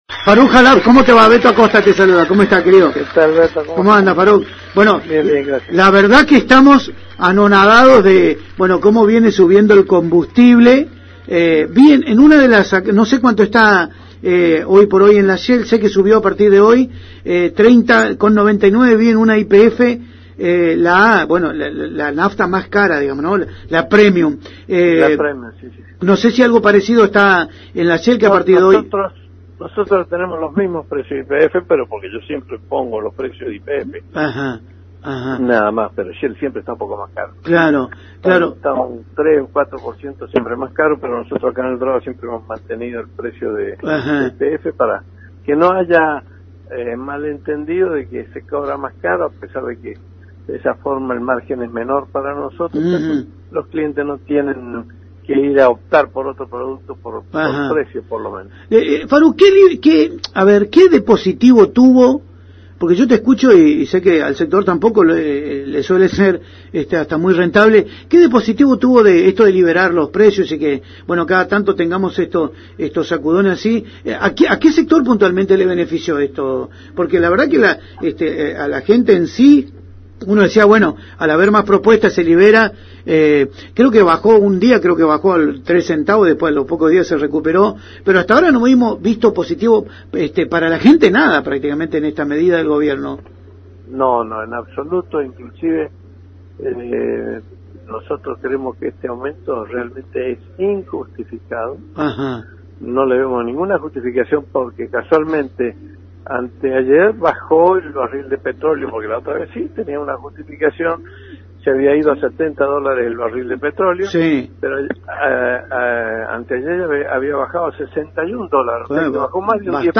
en entrevista con Radio Génesis Eldorado